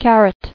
[car·et]